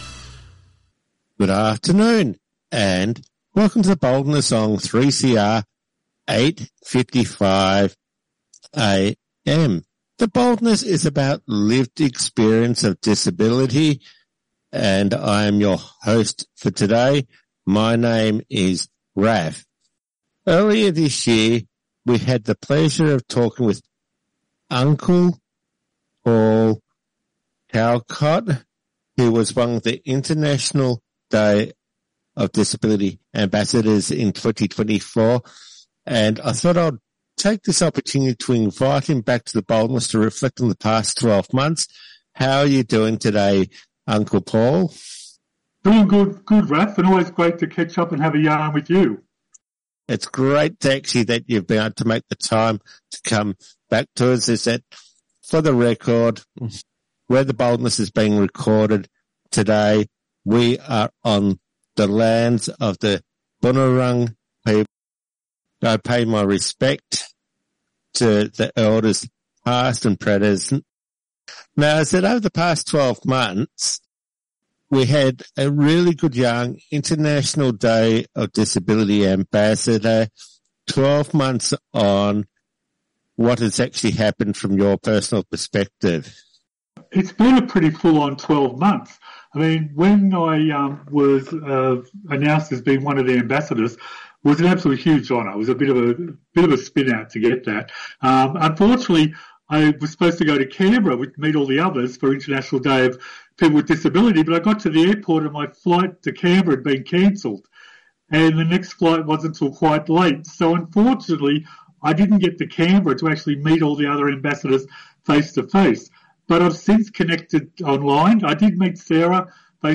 NB All Music is removed from The Podcast